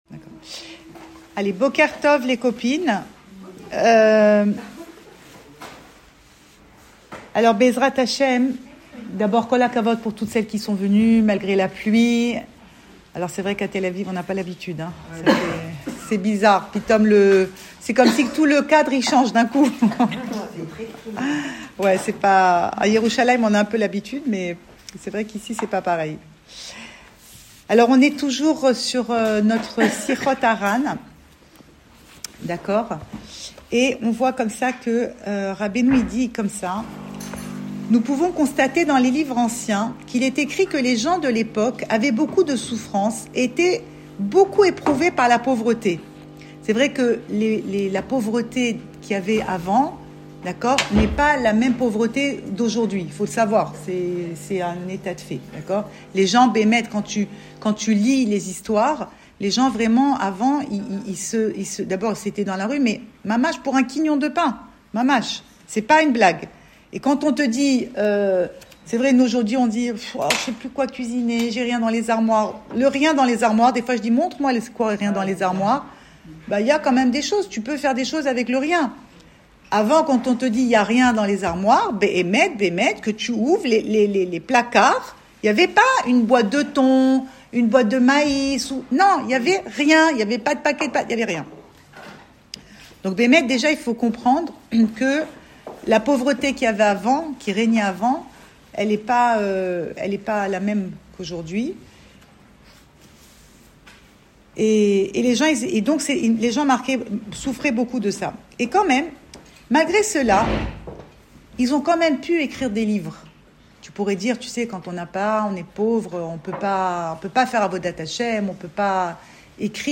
Cours audio Le coin des femmes Le fil de l'info Pensée Breslev - 12 février 2025 13 février 2025 Avant & maintenant. Enregistré à Tel Aviv